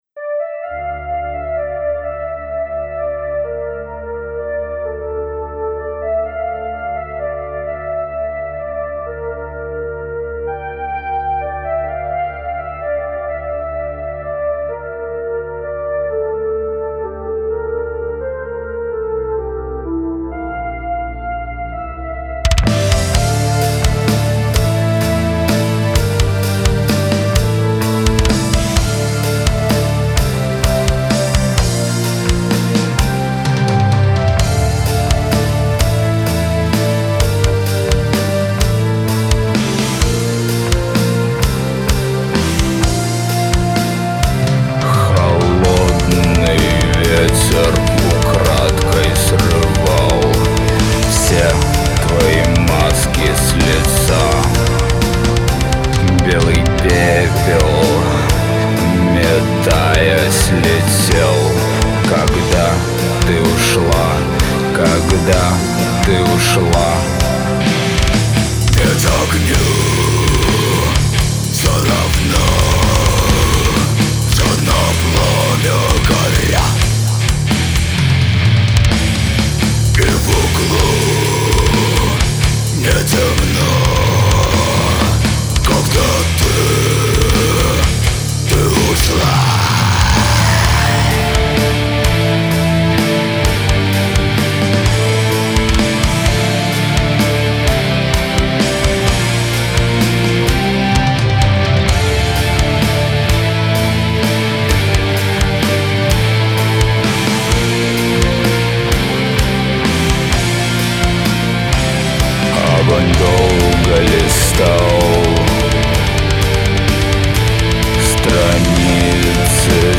Ветераны белорусского Gothic Doom Metal
гитара, бас, сэмплы